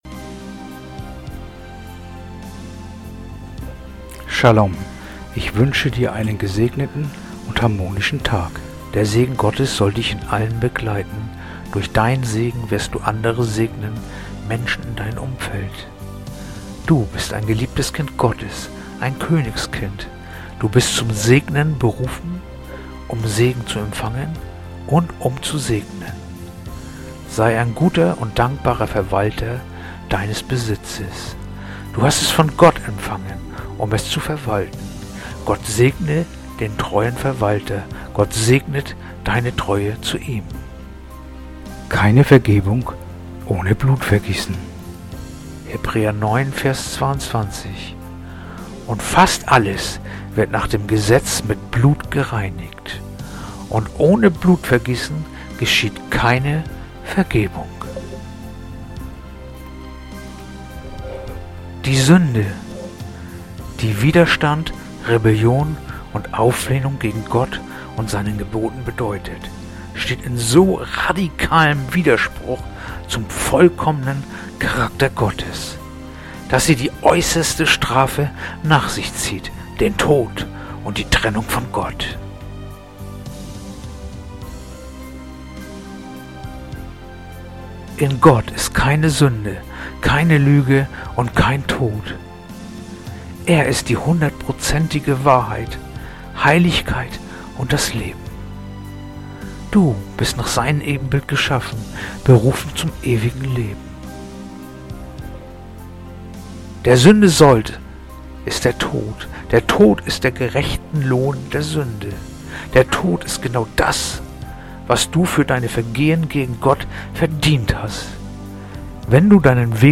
heutige akustische Andacht
Andacht-vom-20-September-Hebräer-9-22.mp3